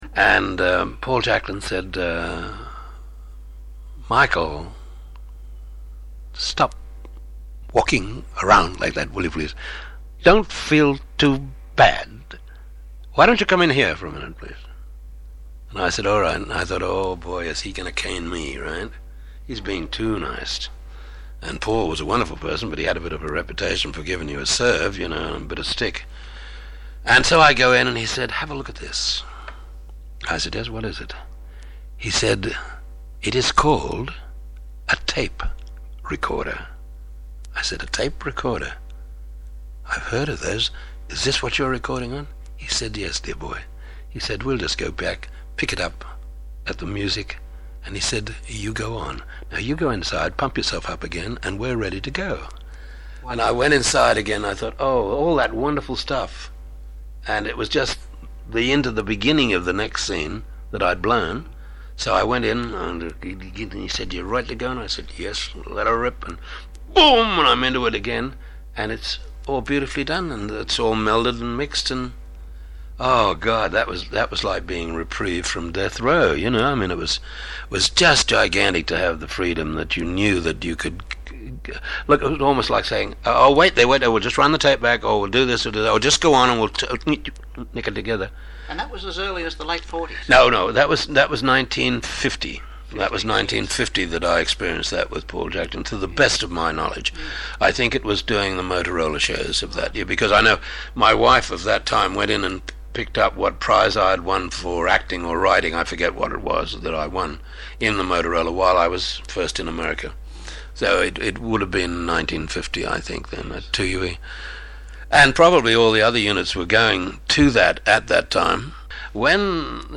The very voluble Michael Pate discusses the advent of technological change and its implications for the radio performer fraternity.